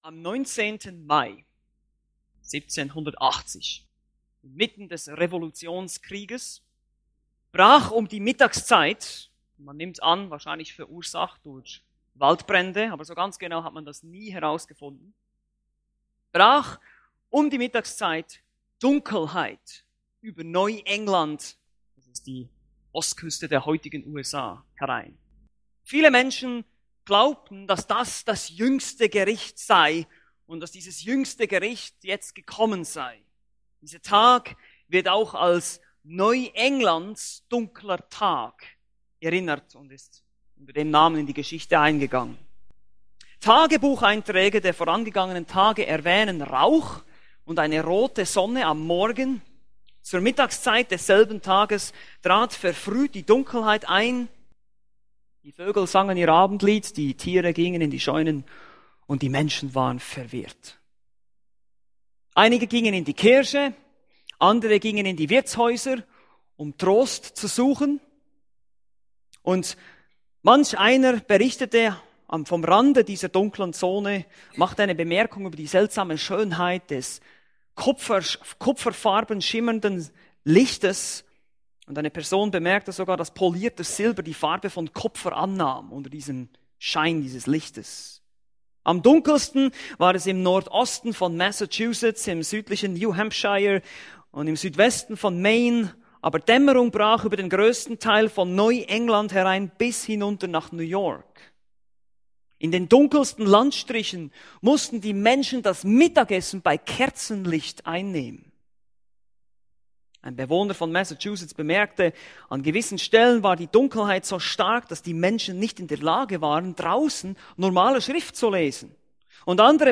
Predigten Übersicht nach Serien - Bibelgemeinde Barnim